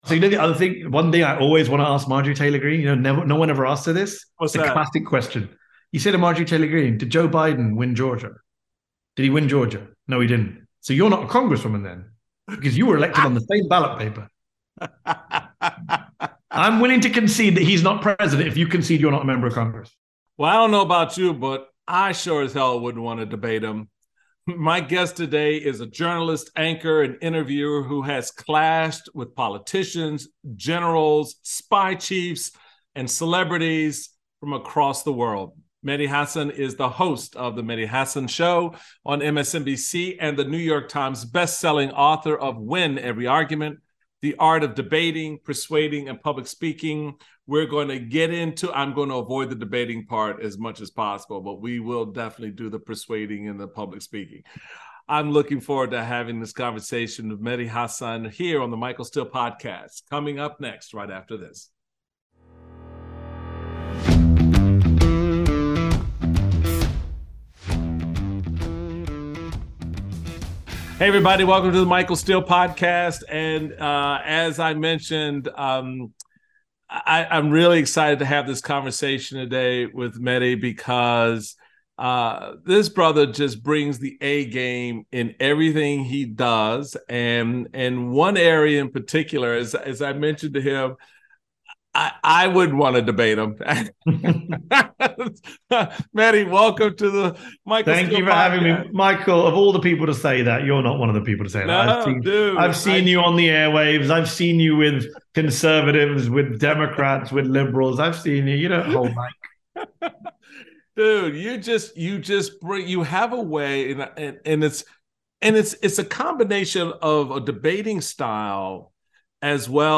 Mehdi Hasan speaks with Michael Steele about the art of debate. The pair discuss the importance of knowing your audience, showing receipts and coming prepared.